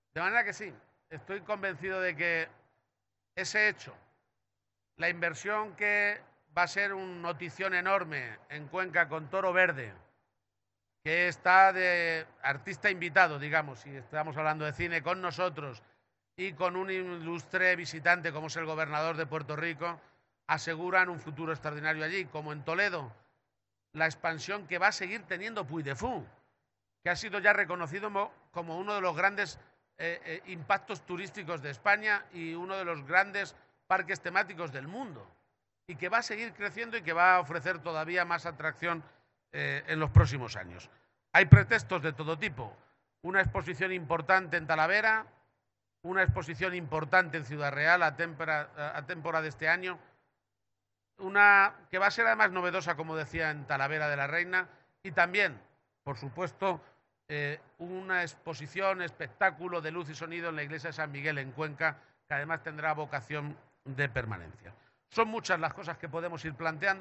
El jefe del Ejecutivo castellanomanchego ha hecho estas declaraciones en la presentación del stand que Castilla-La Mancha tiene este año en la muestra internacional de turismo, en Ifema (Madrid), en la que ha estado acompañado, entre otras autoridades, por el vicepresidente de la Junta, José Luis Martínez Guijarro y la consejera de Economía, Empresas y Empleo, Patricia Franco.